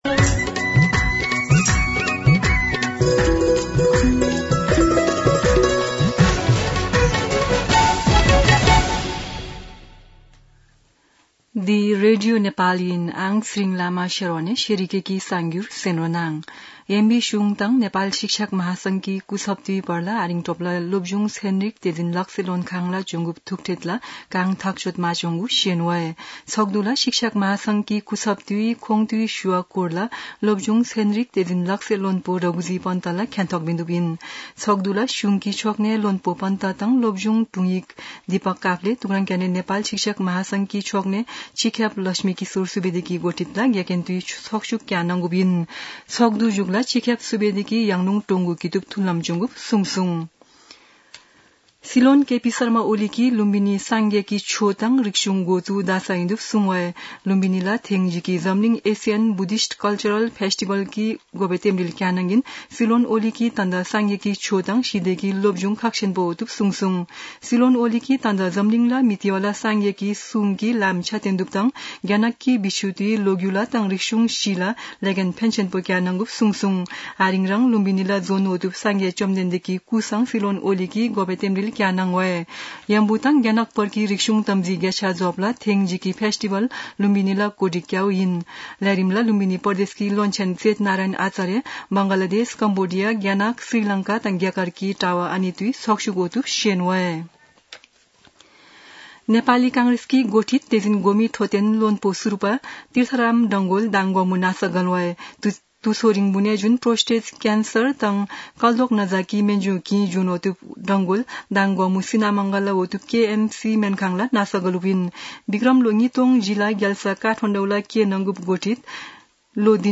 शेर्पा भाषाको समाचार : १३ वैशाख , २०८२
Sherpa-News-13.mp3